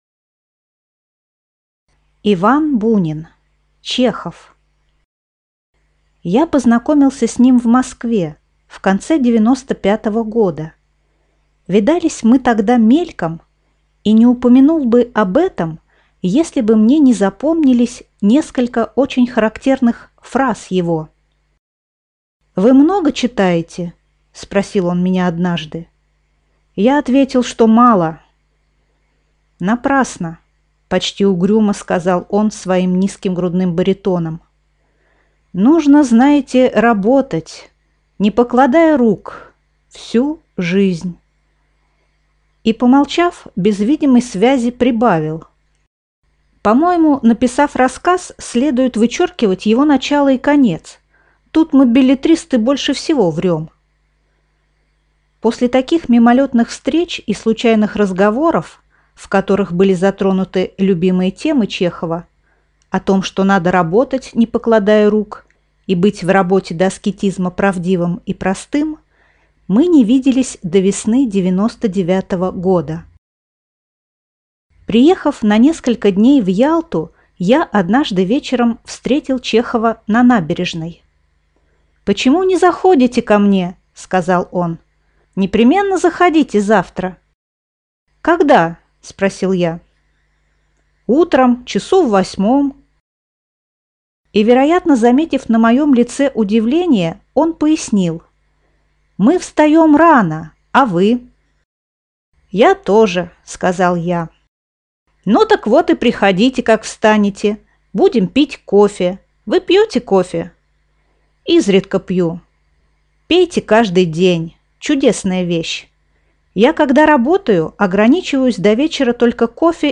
Aудиокнига Чехов Автор Иван Бунин Читает аудиокнигу